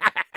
Soldier_laughshort03_de.wav